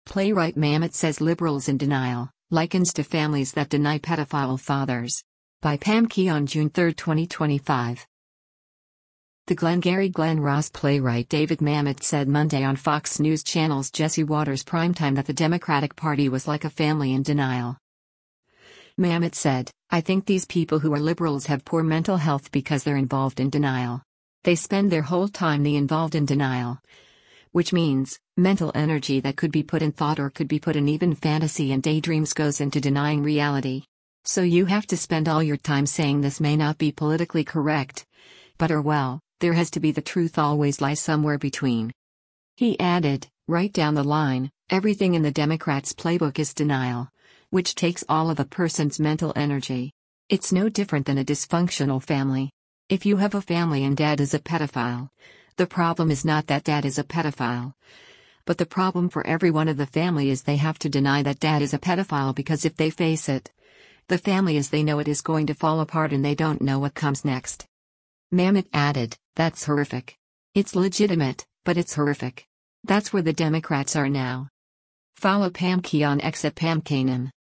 The “Glengarry Glen Ross” playwright David Mamet said Monday on Fox News Channel’s “Jesse Watters Primetime” that the Democratic Party was like a family in denial.